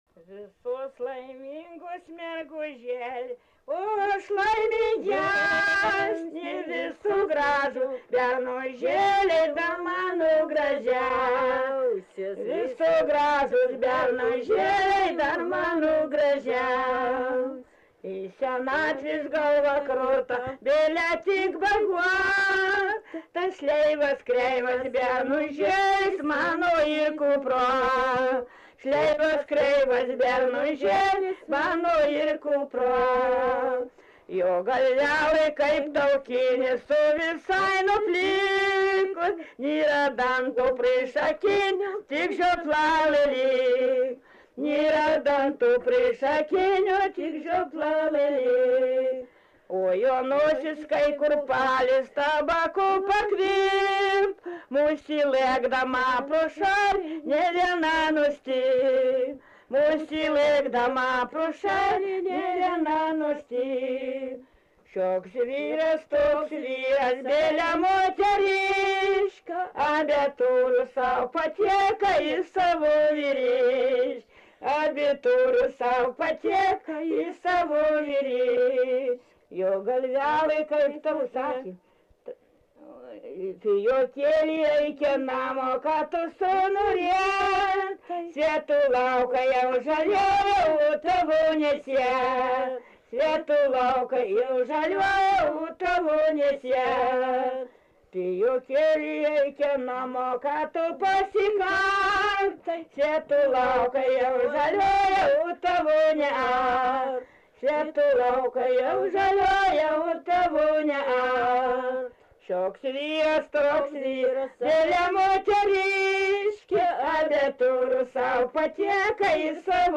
Subject daina
Atlikimo pubūdis vokalinis